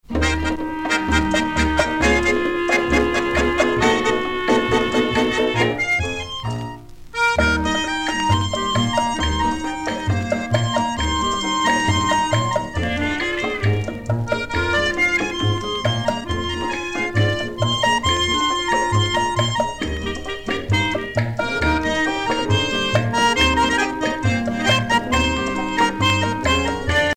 danse : boléro
Pièce musicale éditée